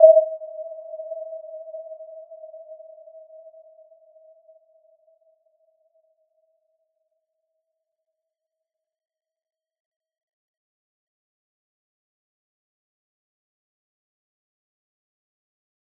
Warm-Bounce-E5-f.wav